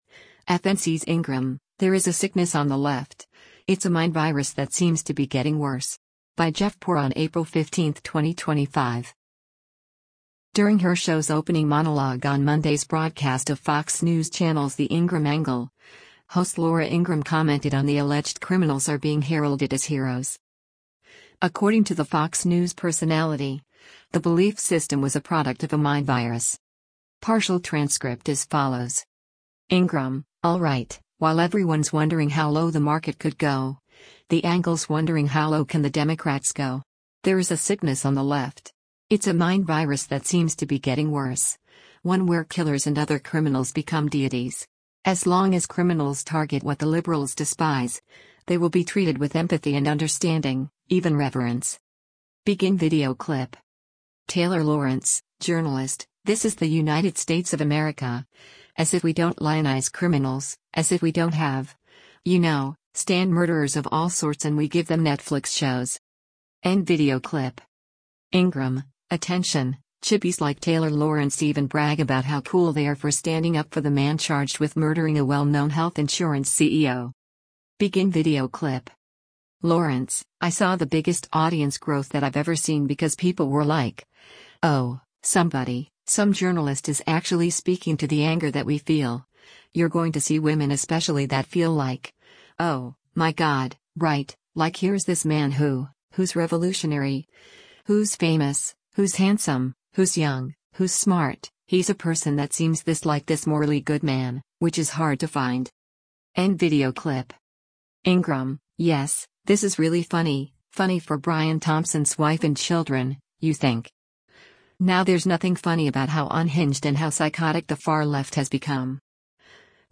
During her show’s opening monologue on Monday’s broadcast of Fox News Channel’s “The Ingraham Angle,” host Laura Ingraham commented on the alleged criminals are being heralded as heroes.